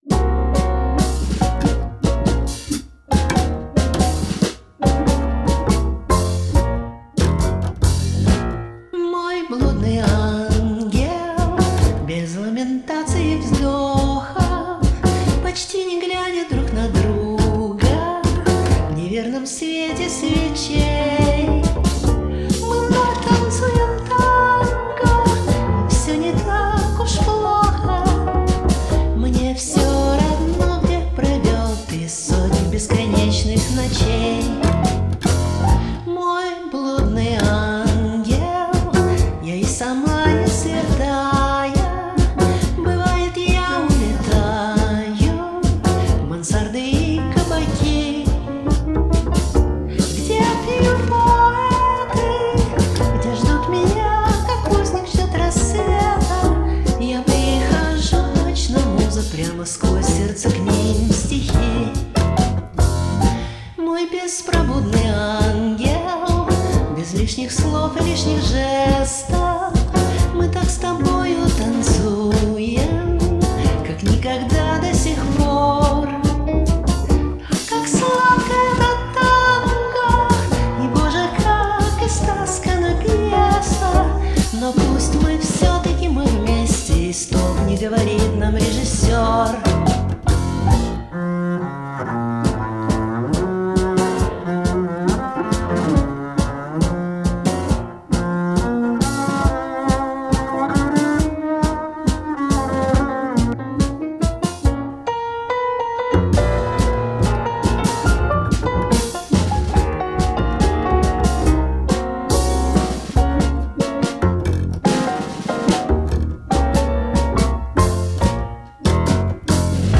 Задорно, легко и по летнему.